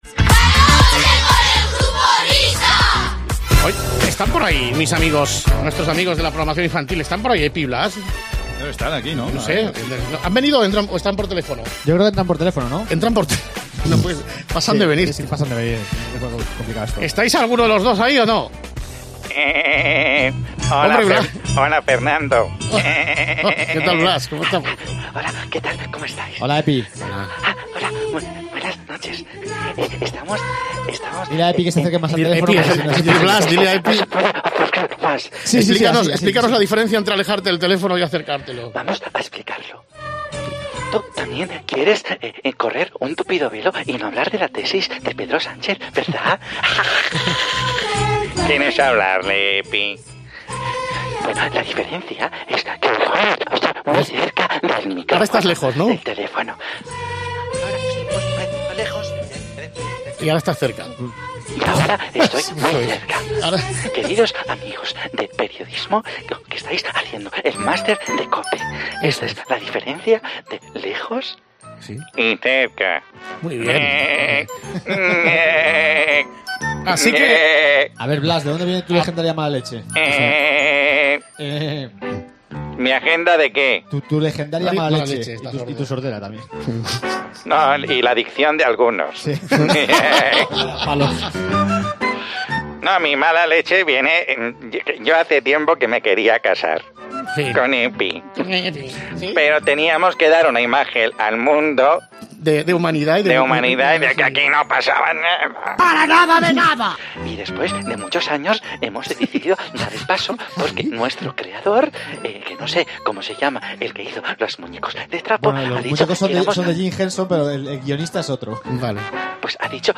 Epi y Blas, en directo con el Grupo Risa tras conocerse que son pareja
Epi y Blas, dos de los protagonistas de la semana después de conocerse que eran pareja , entraron por teléfono este sábado en 'La Noche del Grupo Risa'.